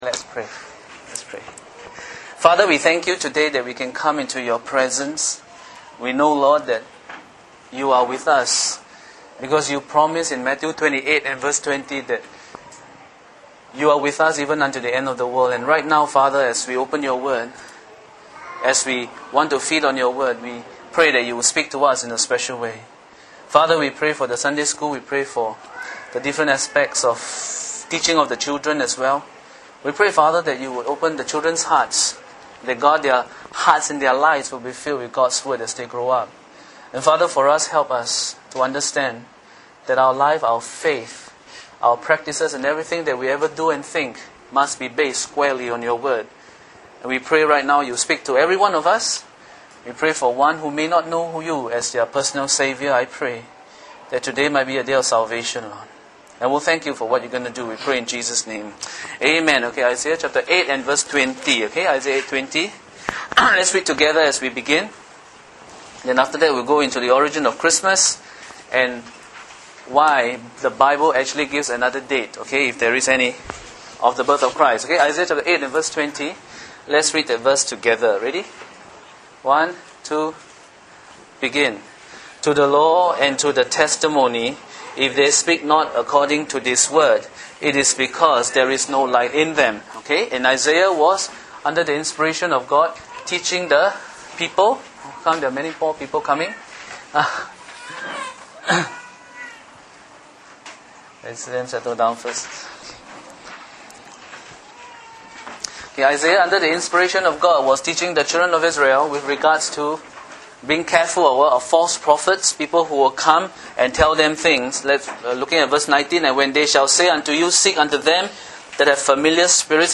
The origin of Christmas Preached by